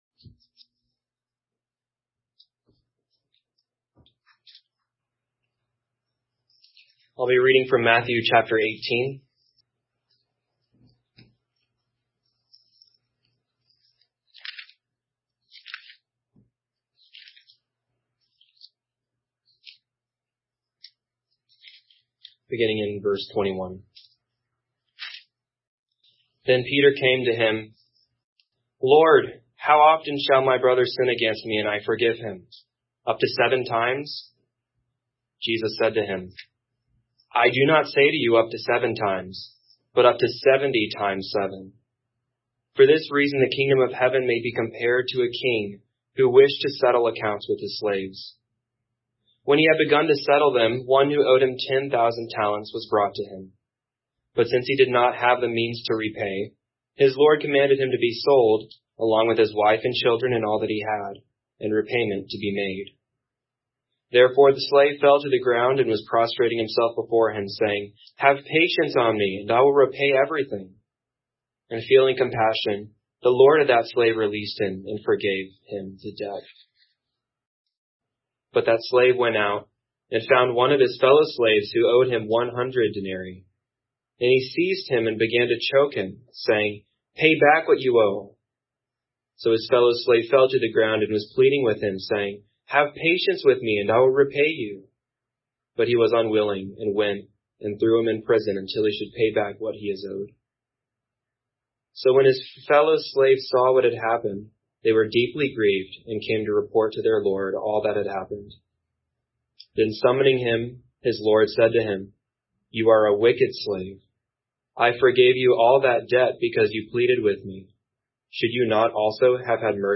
Service Type: Morning Worship Service Topics: Bearing With Each Other , forgiveness